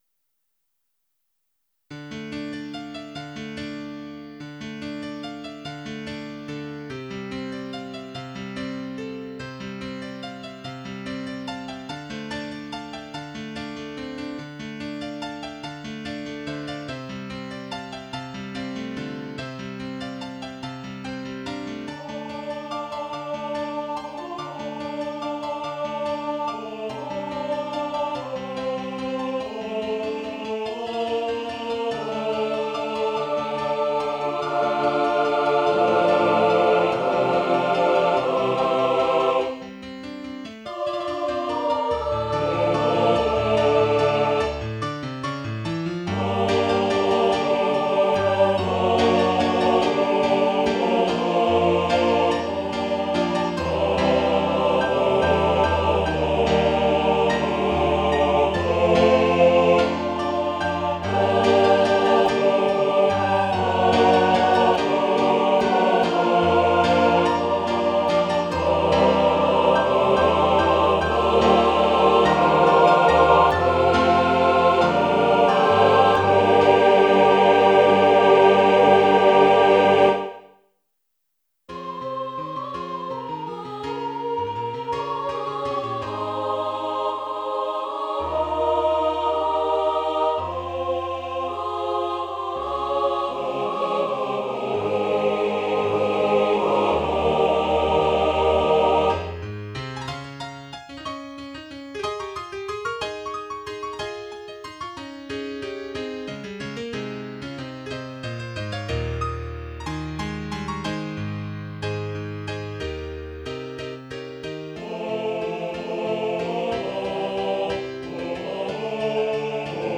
SATB
Piano, Choral Plays